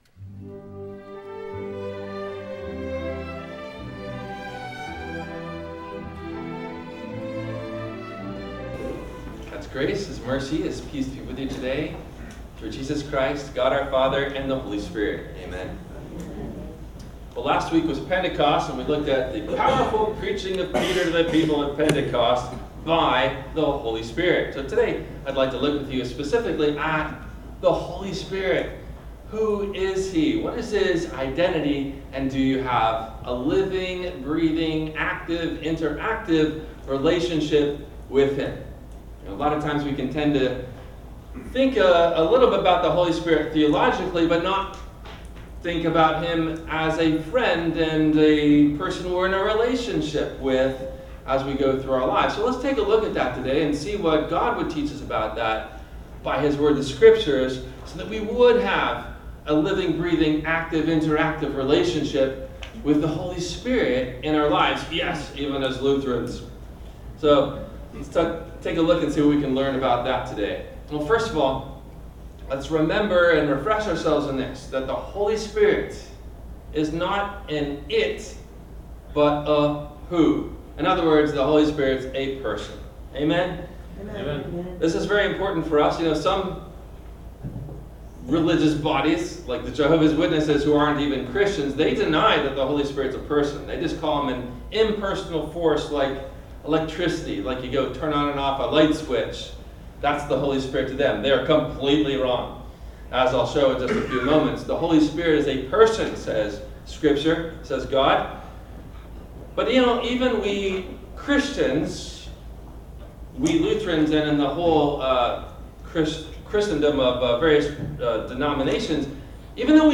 Who Is … The Holy Spirit? – WMIE Radio Sermon – June 30 2025